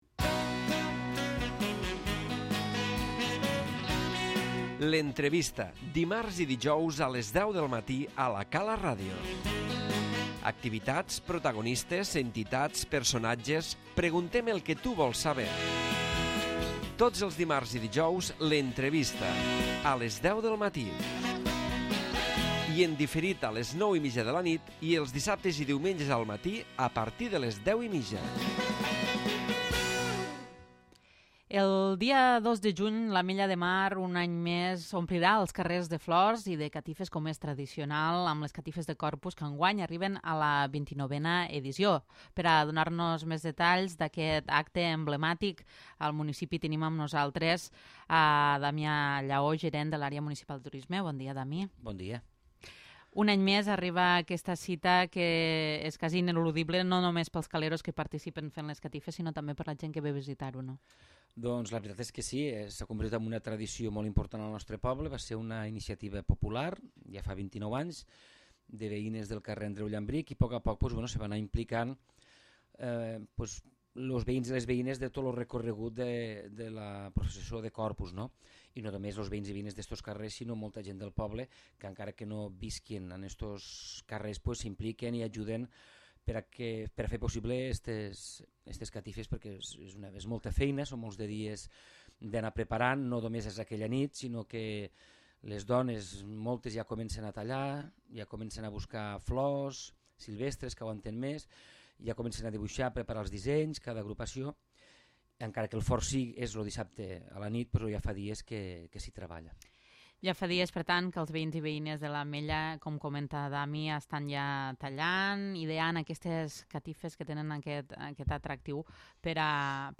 L'Entrevista